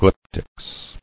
[glyp·tics]